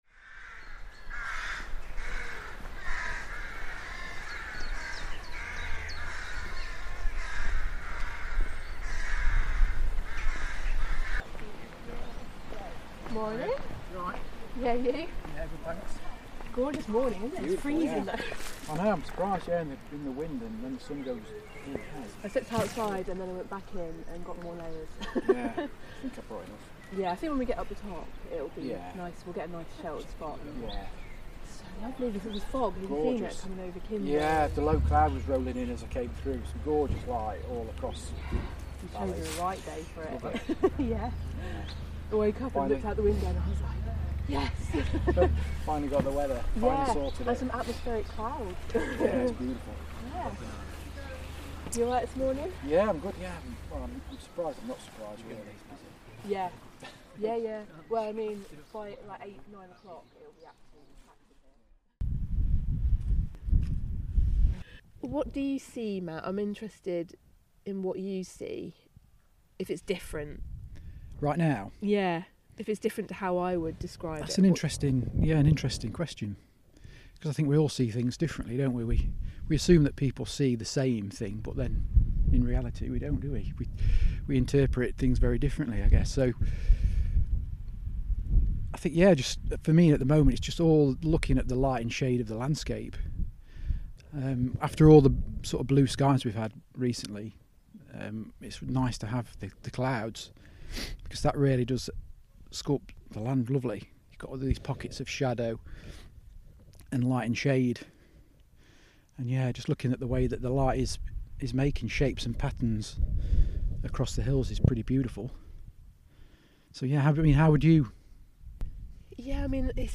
Interview
We recorded this interview below the rocky outcrop of Ringing Roger, sheltered as much as possible from the wind. Listen out for the curlew and grouse in the background.